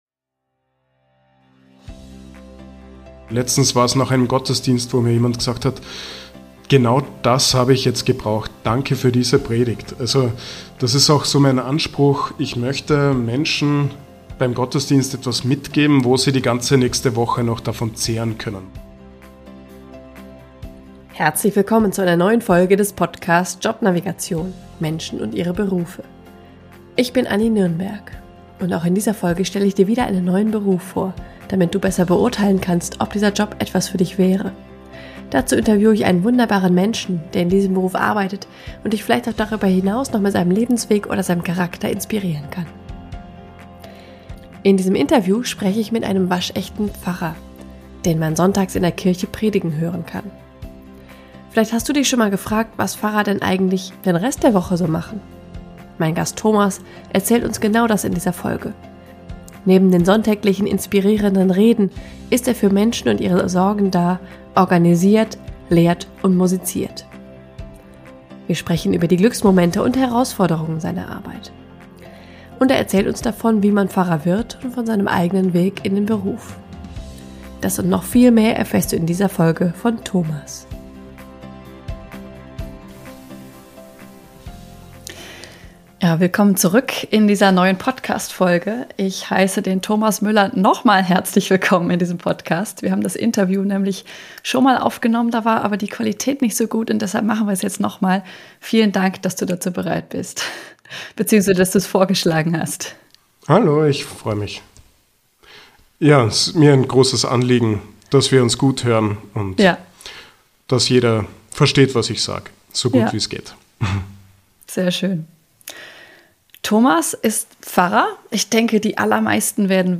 In diesem Interview spreche ich mit einem waschechten Pfarrer, den man sonntags in der Kirche predigen hören kann.